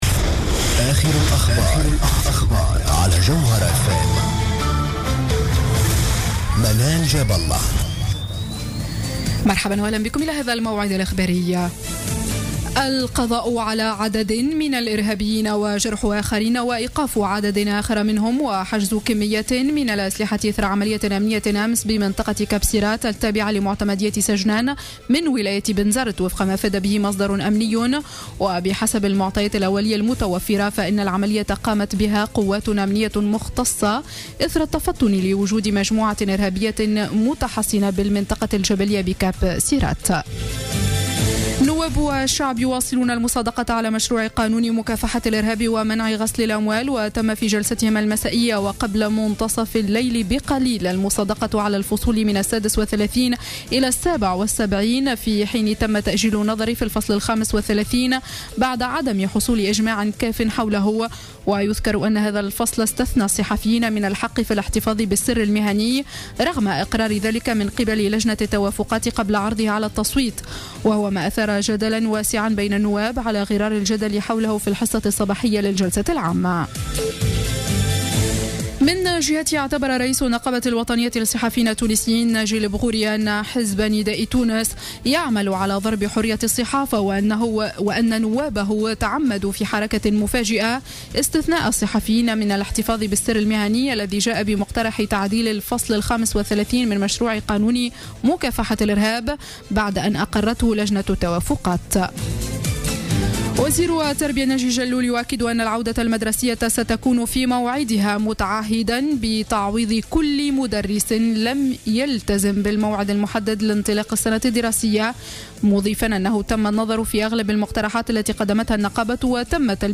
نشرة أخبار منتصف الليل ليوم الجمعة 24 جويلية 2015